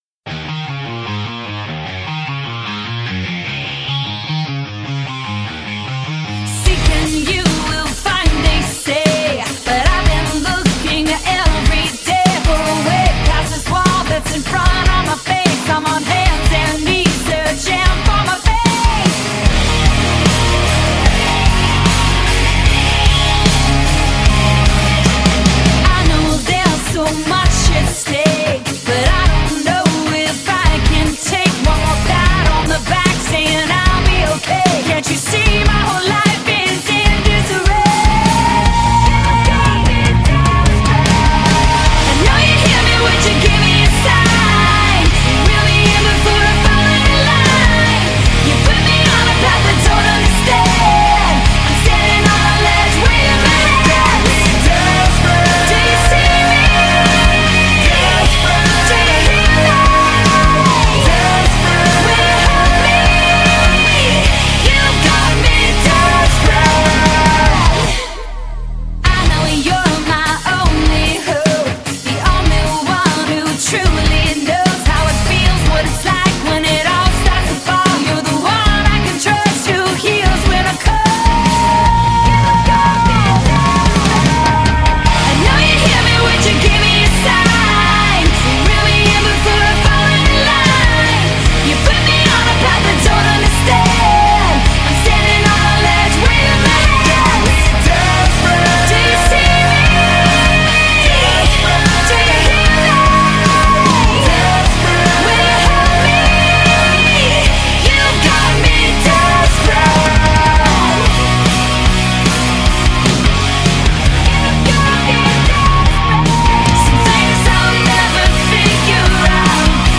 Жанр: Alternative / Rock